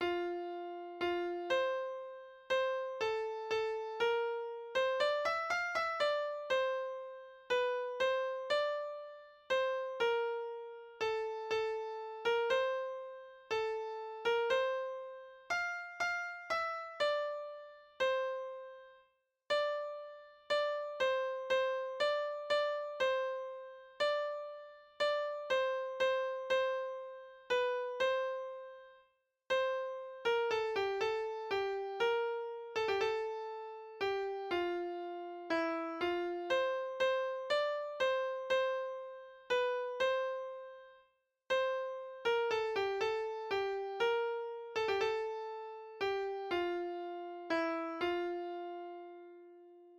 vierstemmig gemengd zangkoor
In bijlagen de gezongen versie van het lied, ook erbij de verschillende stemmen